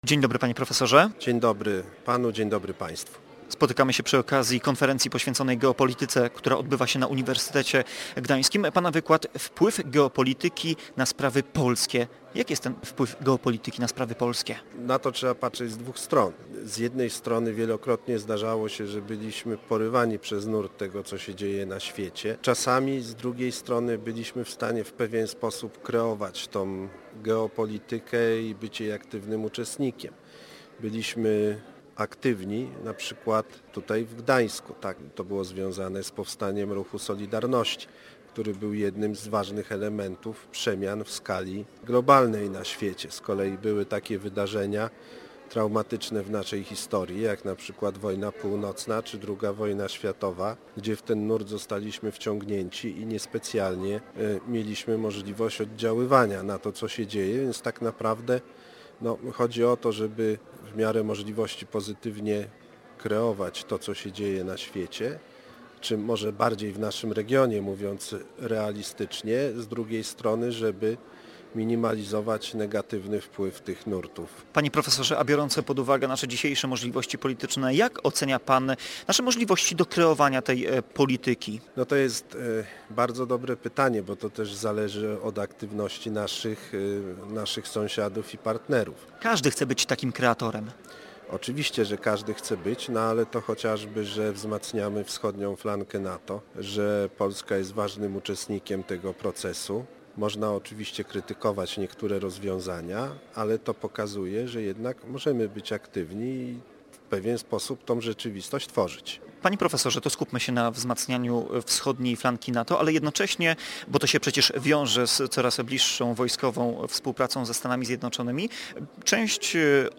Flickr) Tagi: Gość Dnia Radia Gdańsk